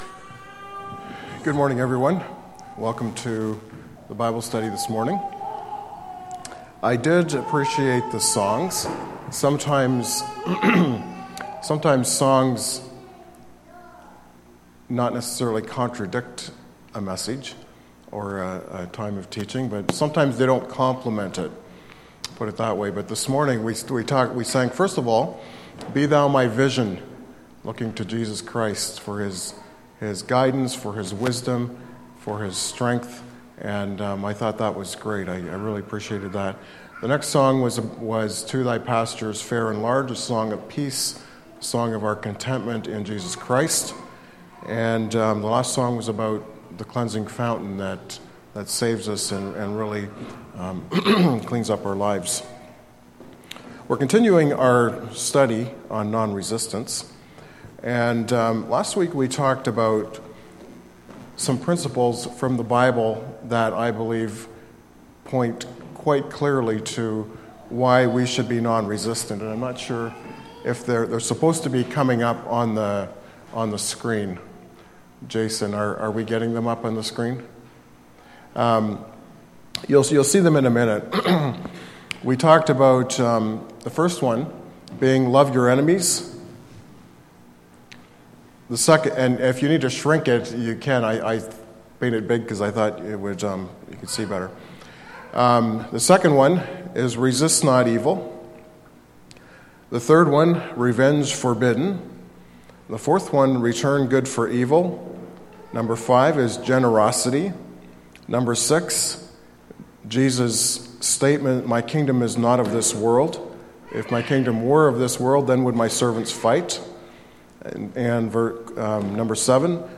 Series: Sunday Morning Bible Study Service Type: Sunday Morning